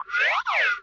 COMEDY - ACCENTS 05
Category: Sound FX   Right: Both Personal and Commercial
Tags: comedy accents electronic sci-fi synth general 4000 general 6000 warner bros hanna barbera slingshot stretch horns bonks boinks sound collisions